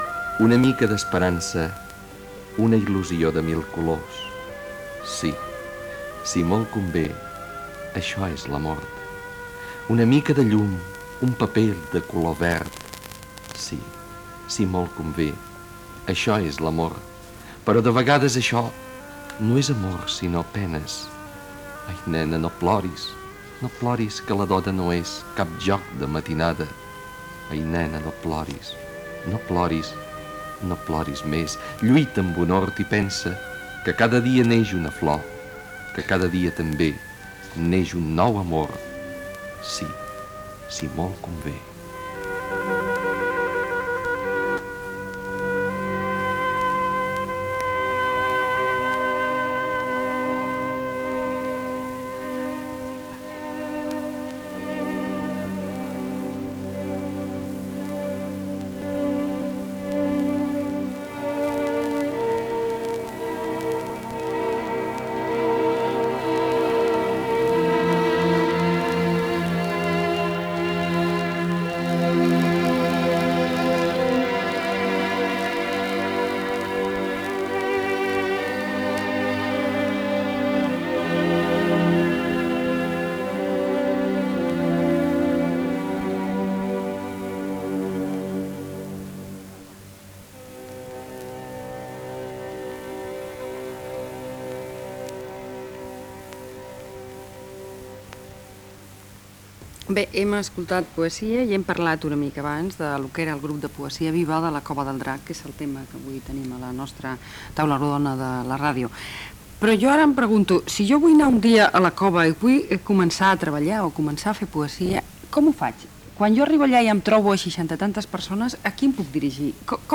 Espai "Taula rodona de la ràdio": lectura d'una poesia i entrevista a un integrant del grup Poesia Viva de la Cova del Drac de Barcelona sobre com participar-hi i sobre com s'organitza el grup
Informatiu